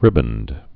(rĭbənd)